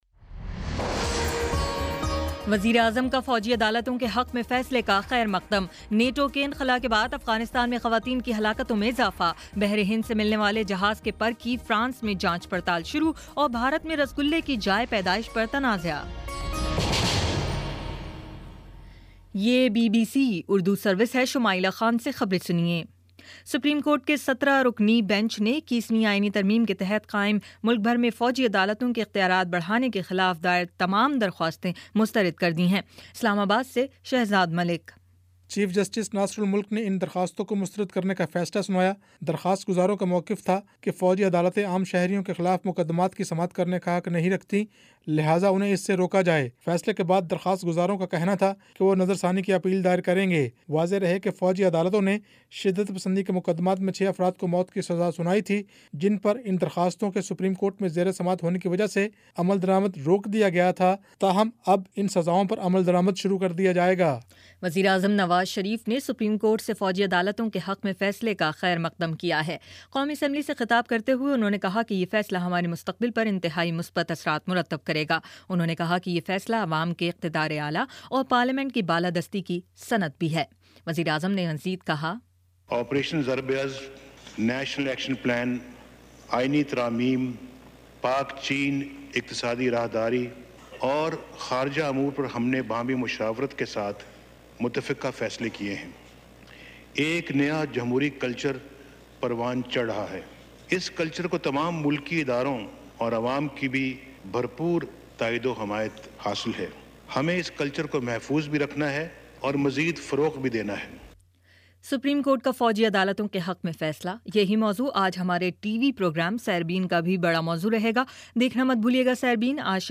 اگست 05: شام سات بجے کا نیوز بُلیٹن